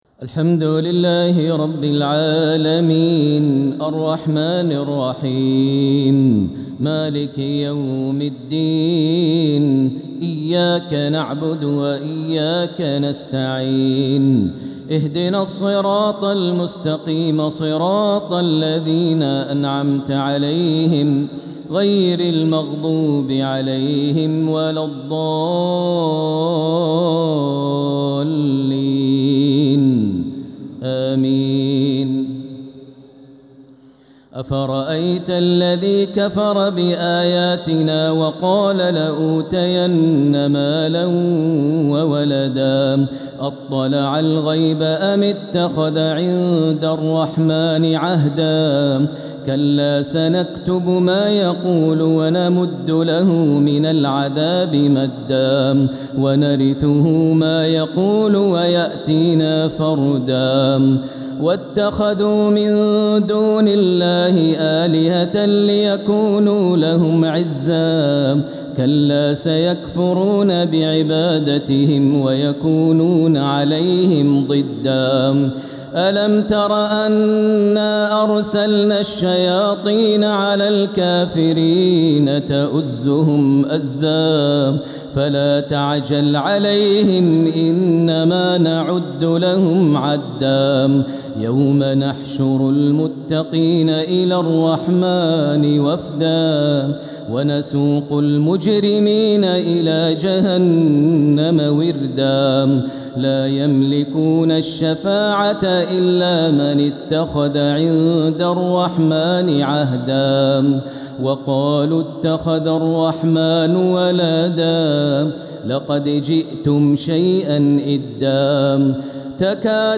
تراويح ليلة 21 رمضان 1447هـ من سورتي مريم (77-98) و طه (1-76) | Taraweeh 21st night Ramadan 1447H Surah Maryam and TaHa > تراويح الحرم المكي عام 1447 🕋 > التراويح - تلاوات الحرمين